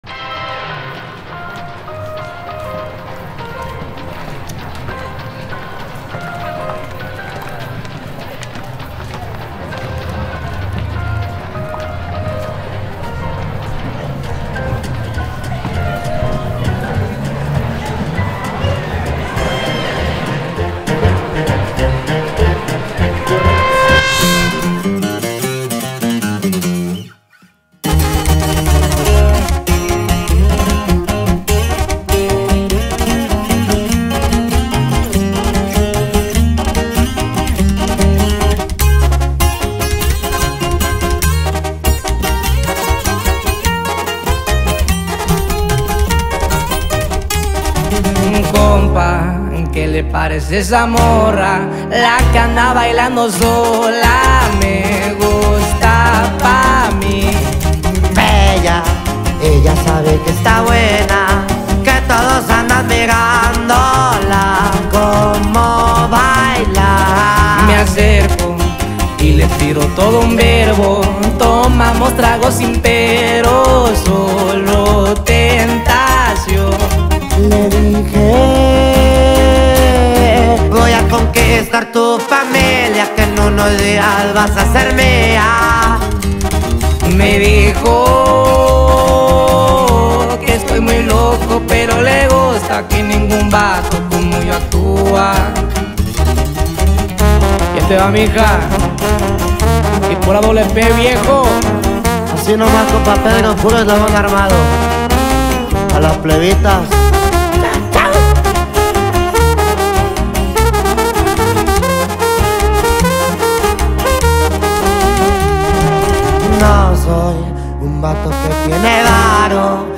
2024-12-14 18:03:25 Gênero: Trap Views